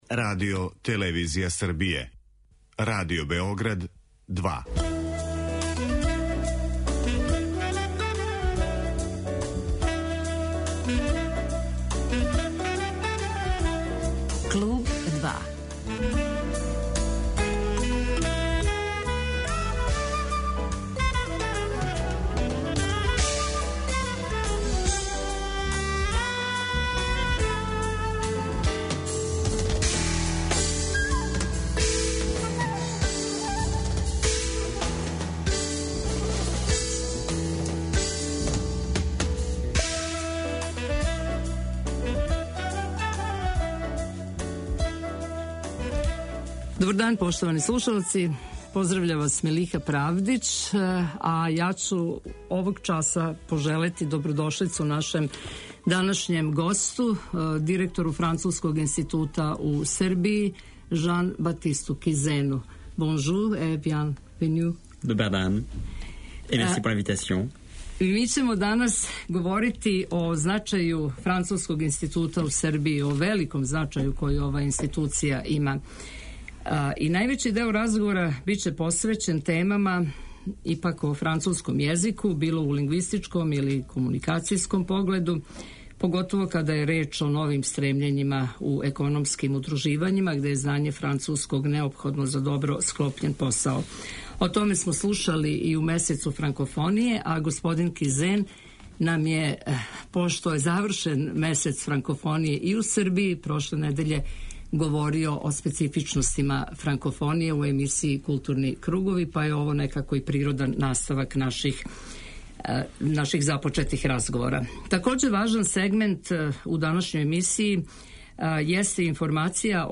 Највећи део разговора биће посвећен темама о француском језику, било у лингвистичком или комуникацијском погледу - поготово када је реч о новим стремљењима у економским удруживањима где је знање француског неопходно за добро "склопљен" посао. Такође важан сегмент емисије јесте информисање о могућностима да се студира у Француској на свим академским нивоима, о условима уписа и живота у току студија.